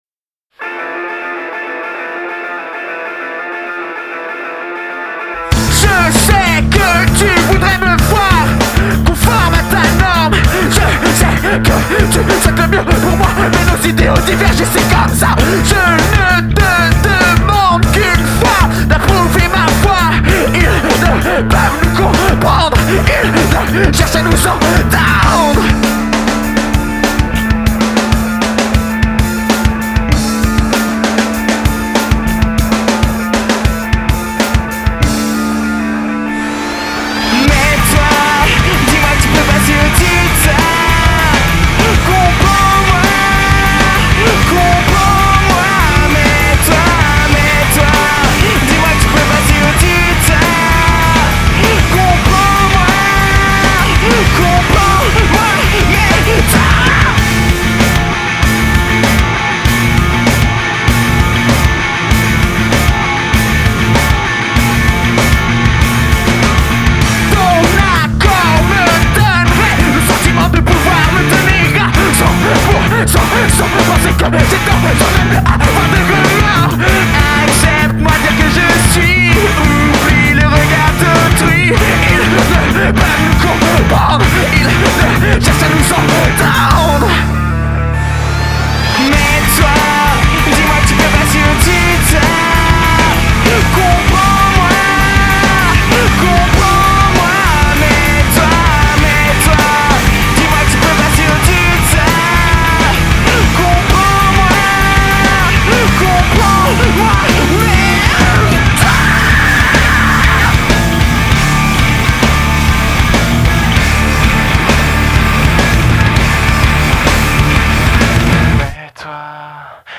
[Neo Metal/Emocore]
Vocals
Guitars
Bass
Drums